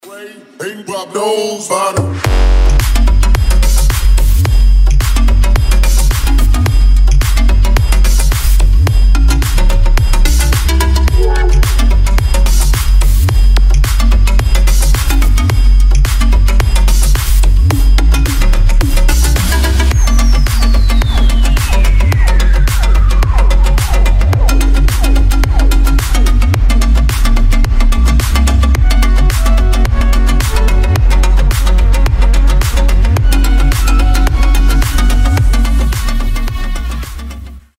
• Качество: 320, Stereo
громкие
мощные басы
future house
electro house